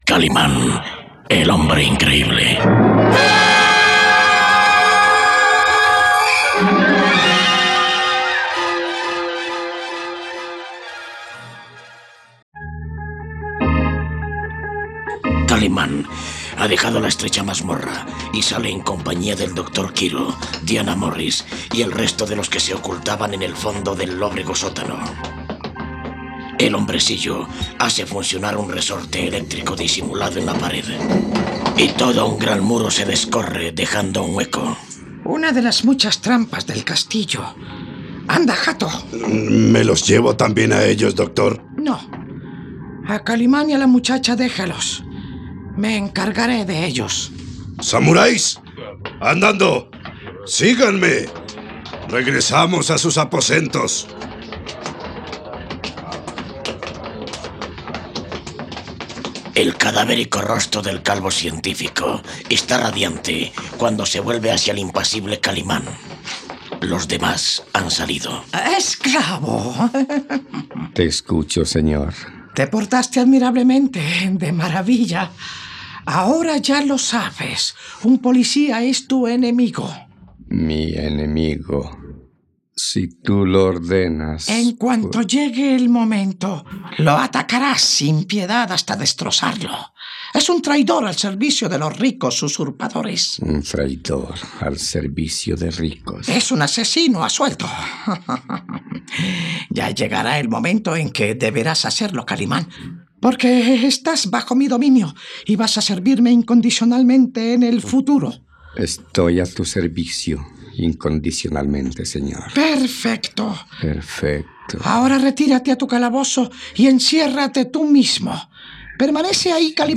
radionovela